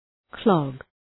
Shkrimi fonetik {klɒg}
clog.mp3